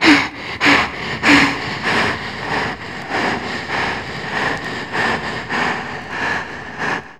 RUNVOX    -L.wav